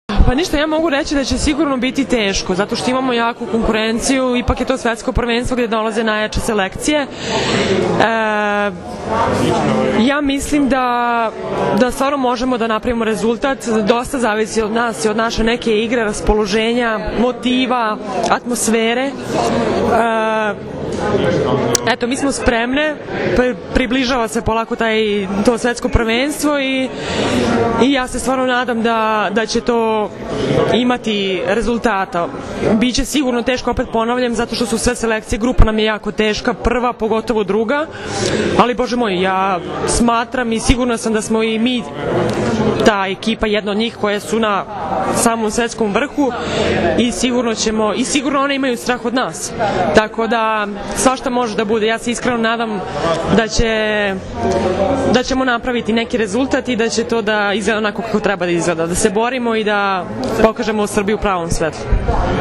IZJAVA STEFANE VELJKOVIĆ